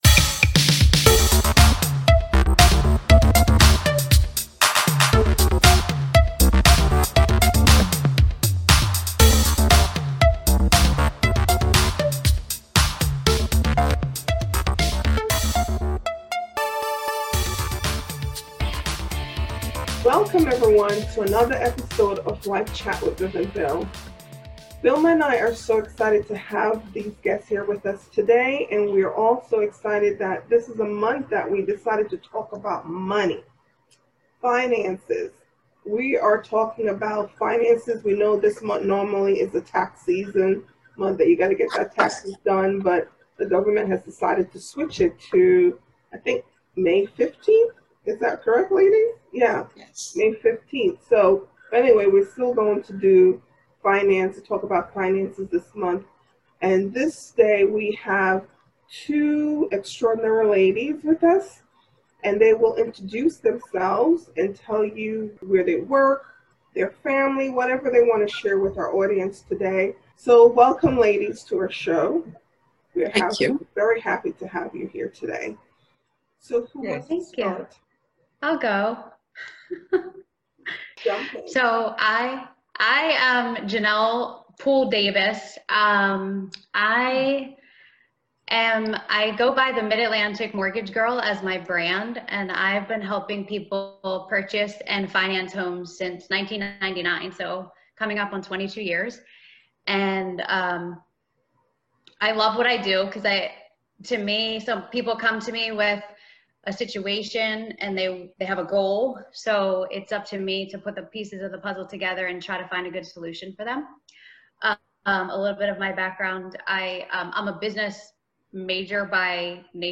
In today’s video, we have two extraordinary women who discuss finance, ways to manage your money and the importance of credit.